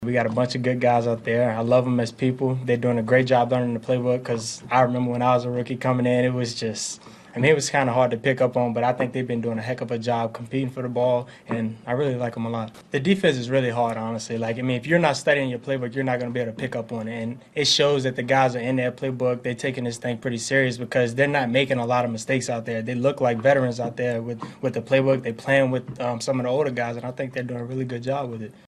Defensively, safety Juan Thornhill says they are loaded.